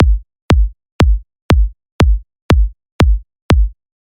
ROOT深宅大鼓2
标签： 120 bpm Deep House Loops Drum Loops 690.13 KB wav Key : Unknown
声道立体声